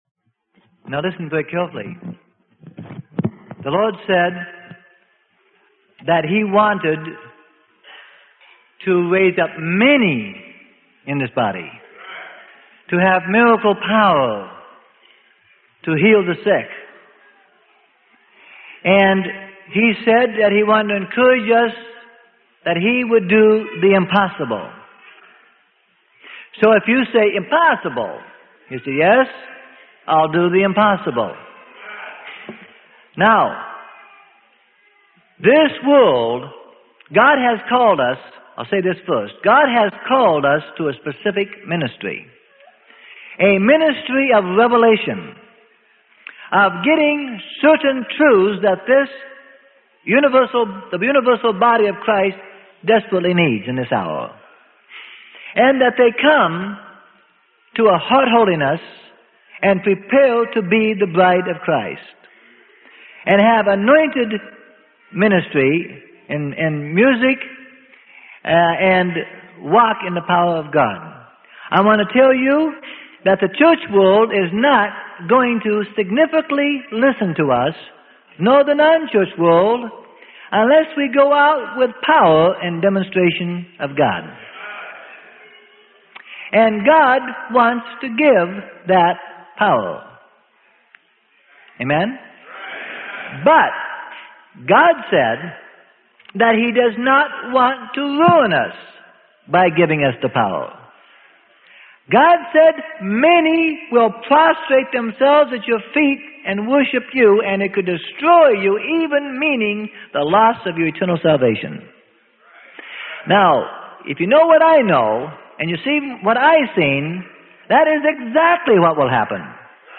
Sermon: How to Receive Miracle Power Safely - Freely Given Online Library